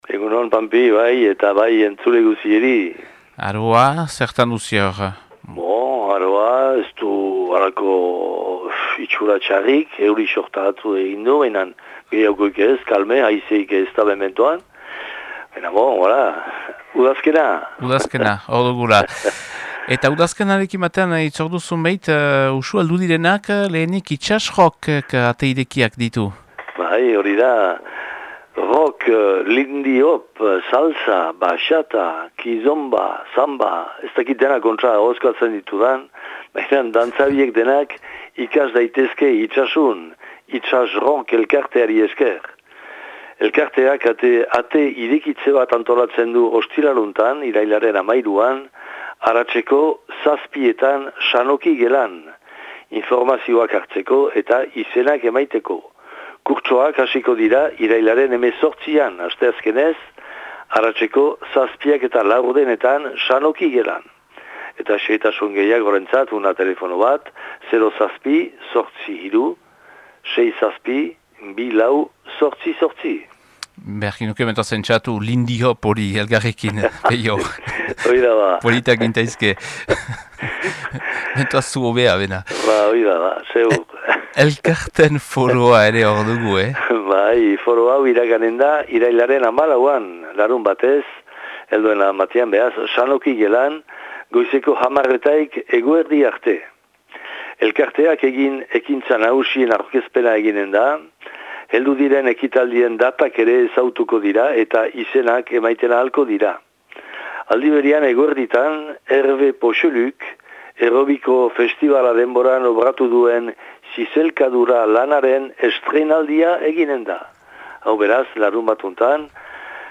Irailaren 9ko Itsasuko berriak